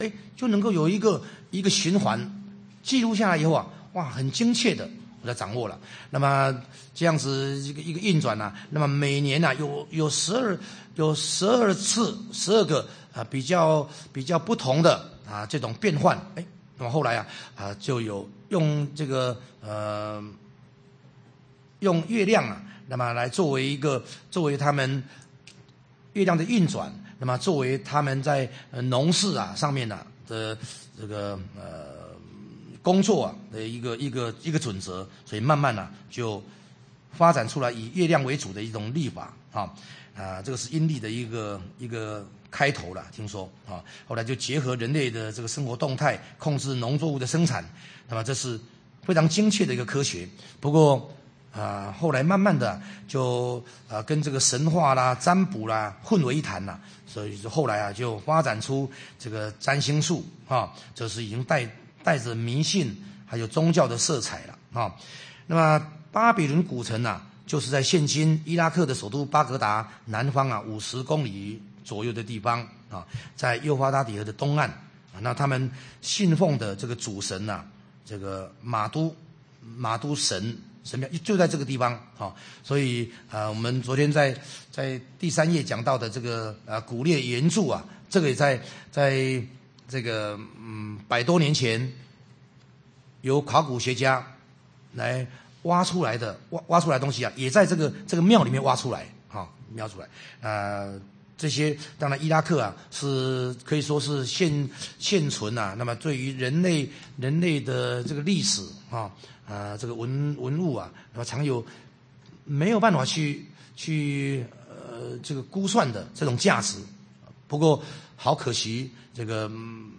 講習會
地點 台灣總會 日期 08/10/2008 檔案下載 列印本頁 分享好友 意見反應 Series more » • 以斯拉記 11-1 • 以斯拉記 11-2 • 以斯拉記 11-3 …